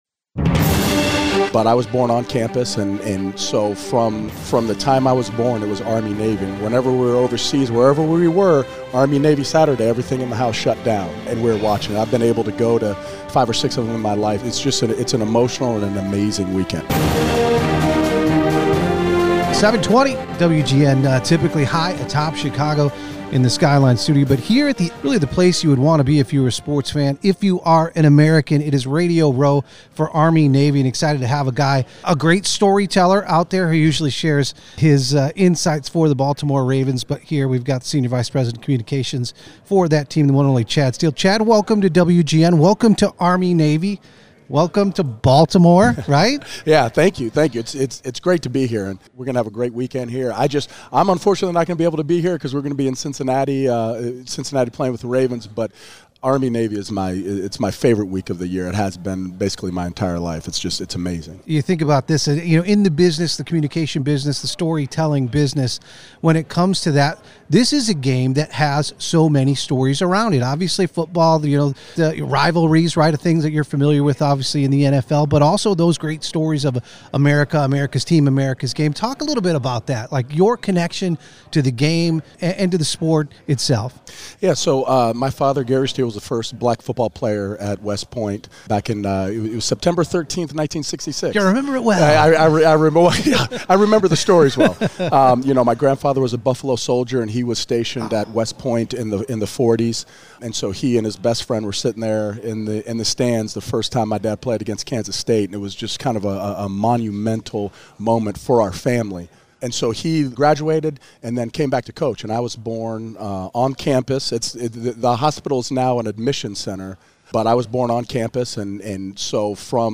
live on WGN Radio’s Veteran Voices on USAA Radio Row before Army Navy